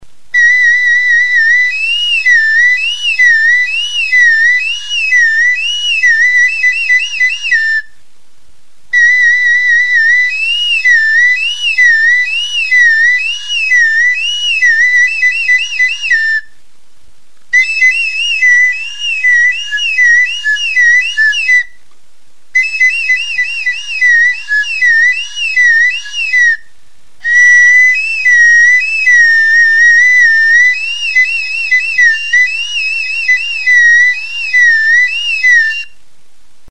TXULUBITA | Soinuenea Herri Musikaren Txokoa
Gazte bat txulubita jotzen.
Lizar makilarekin egindako txulubita da.
Tutua sartu eta atereaz tonu aldaketak ematen ditu.
TXULUBITA Classification: Aérophones -> Flûtes -> À piston Emplacement